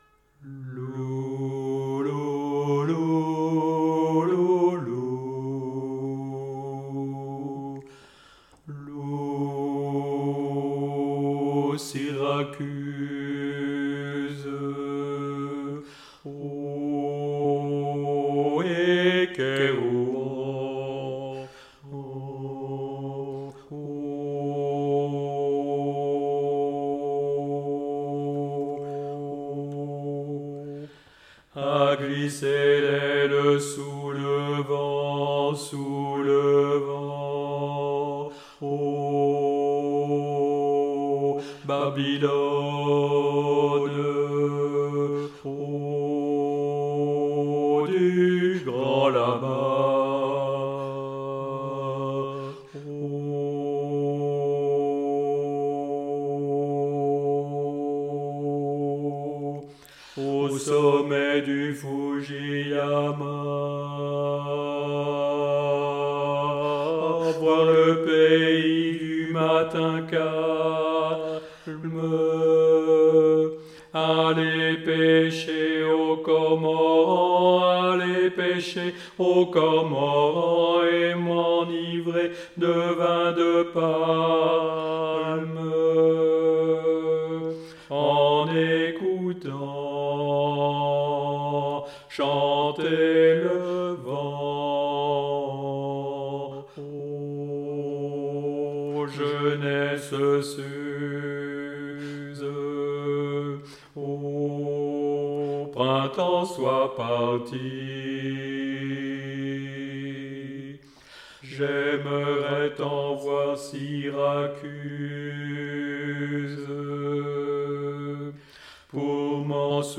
harmonisation
Soprano